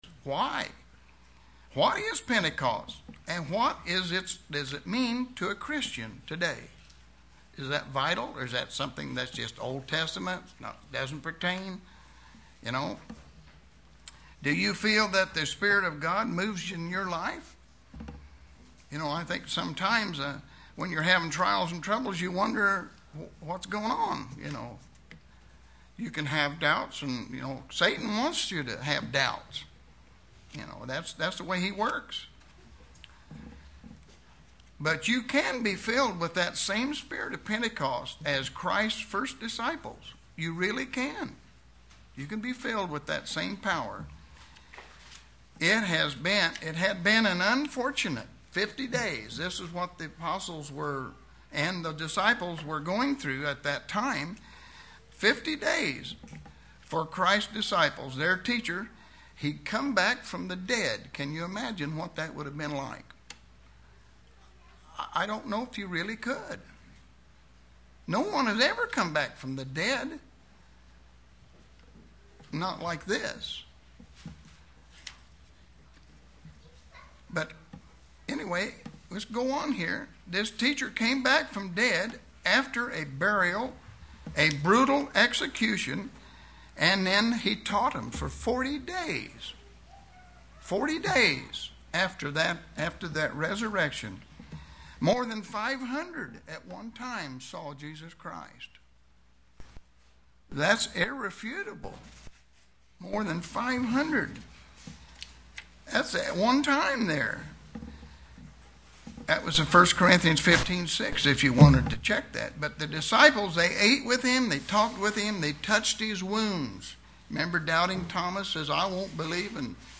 Given in Terre Haute, IN
UCG Sermon Studying the bible?